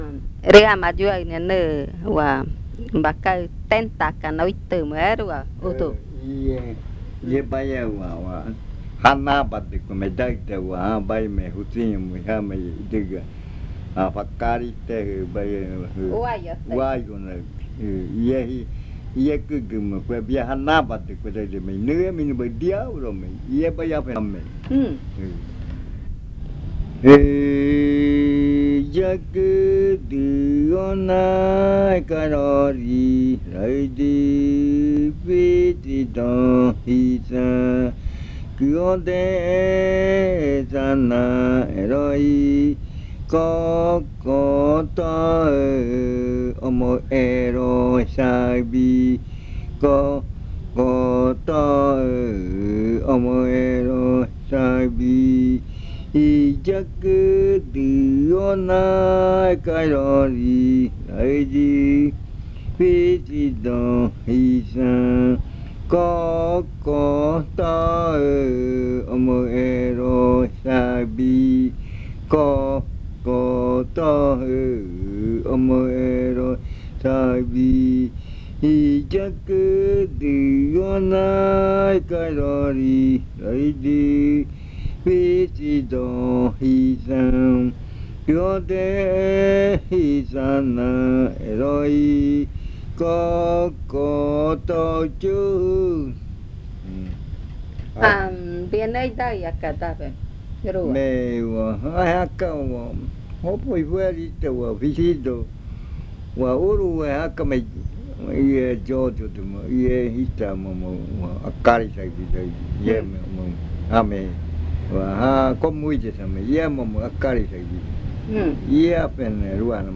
Leticia, Amazonas
Canto en murui.
Chant in Murui.